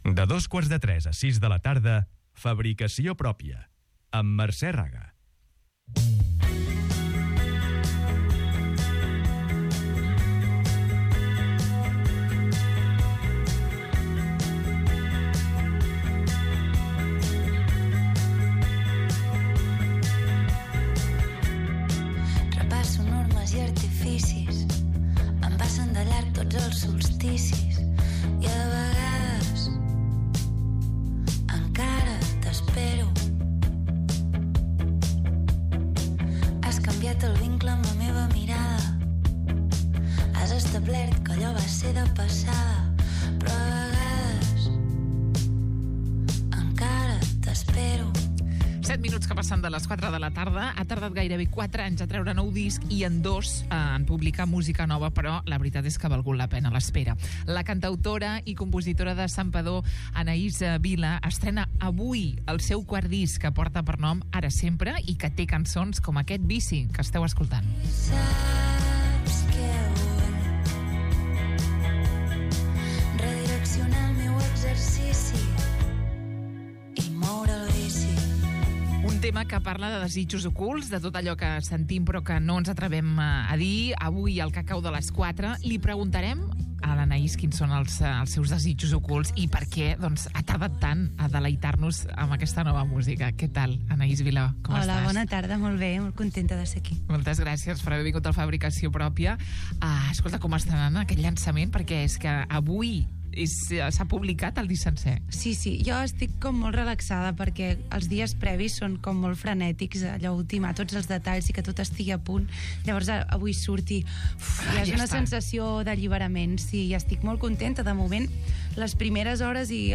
Un tema que la de Santpedor toca per primera vegada en directe i en acústic als estudis de Ràdio Estel.